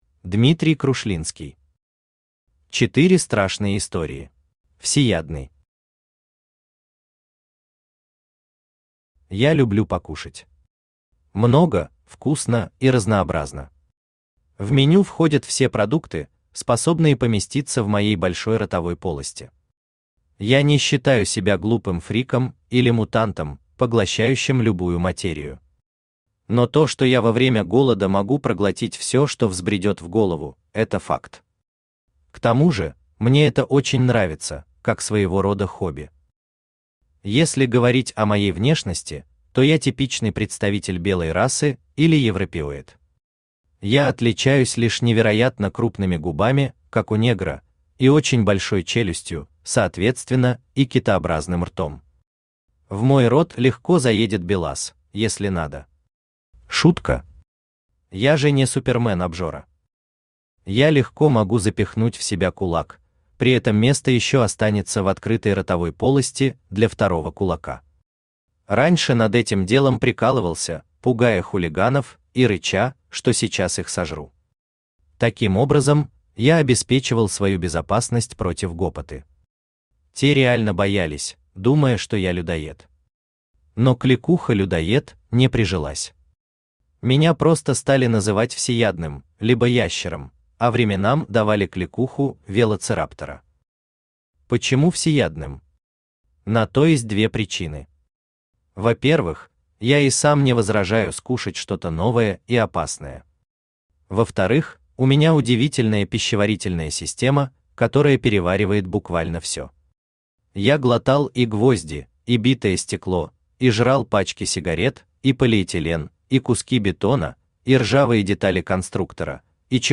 Аудиокнига Четыре страшные истории | Библиотека аудиокниг
Aудиокнига Четыре страшные истории Автор Дмитрий Сергеевич Крушлинский Читает аудиокнигу Авточтец ЛитРес.